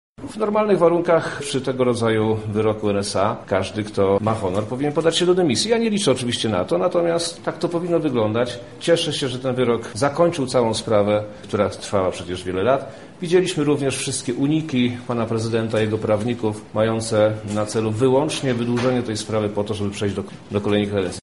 Zdaniem wojewody lubelskiego, Przemysława Czarnka, wyrok w tej sprawie był całkowicie zasadny, bo prawo zostało złamane w sposób ewidentny:
Wojewoda o wyroku